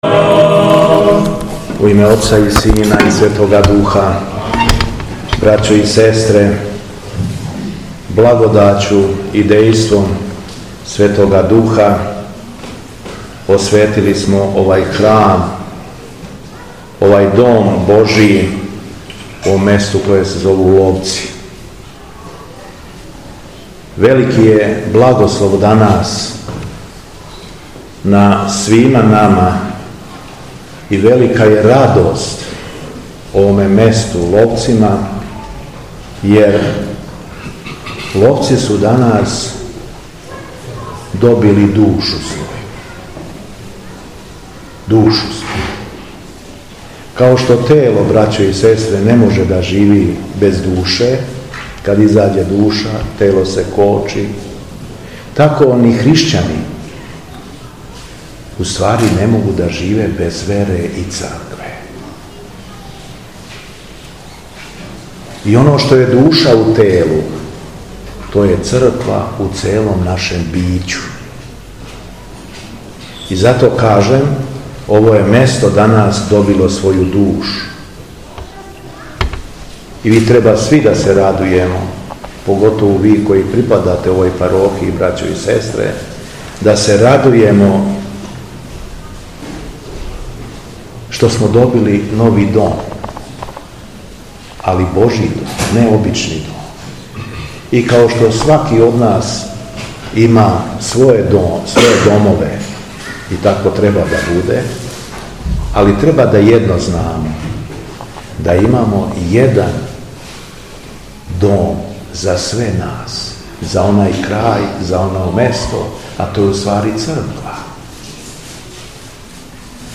У дан молитвеног прослављања Светог Вавиле и Пророка Мојсеја, те свештеномученика Петра Дабробосанског у нашој Светосавској Цркви, 4/17. септембра 2023. године, Његово Преосвештенство Епископ шумадијски Господин Јован, служио је у селу Ловци, код Јагодине. Звучни запис беседе Том приликом осветио је новоподигнуту цркву Свете Тројице положивши честицу моштију Светог великомученика косовског кнеза Лазара у часну трпезу.